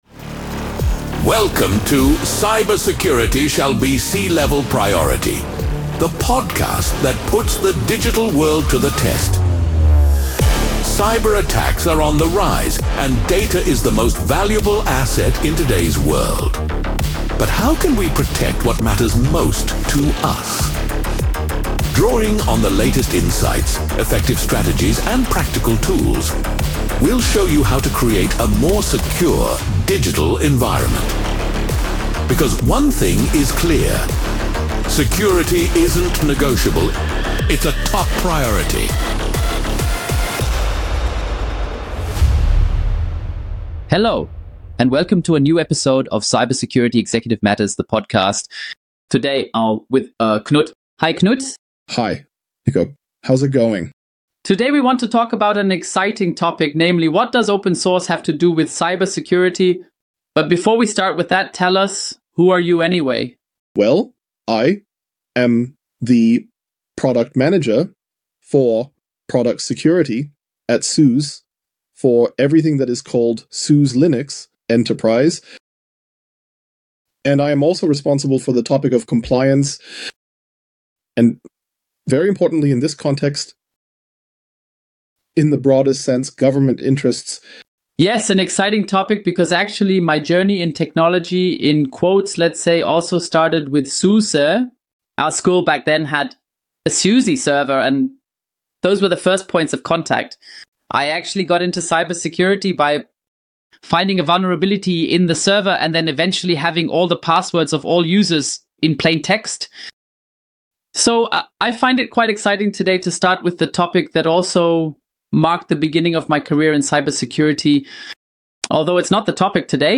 Webpage ____________________________________________ 🚨 This Podcast is translated from the original content Cybersecurity ist Chefsache using AI technology to make them accessible to a broader audience.